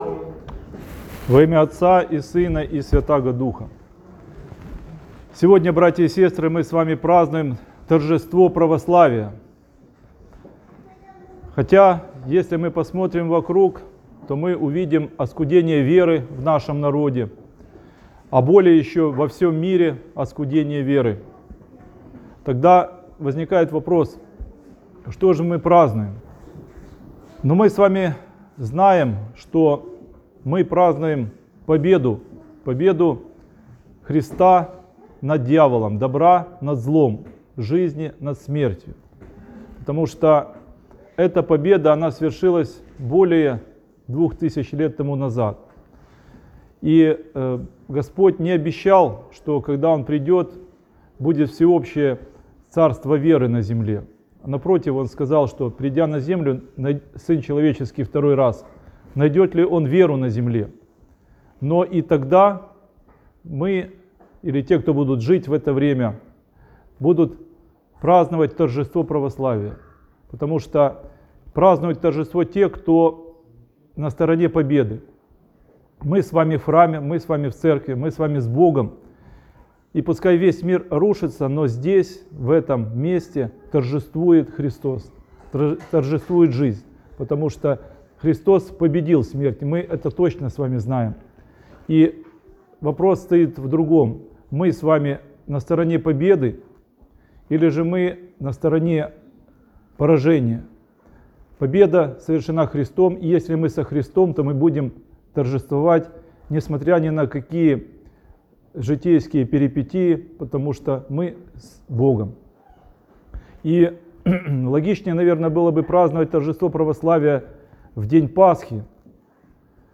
Как привести близких к Богу? Неделя Торжества Православия. Проповедь (АУДИО) | Макариевский храм, г. Киев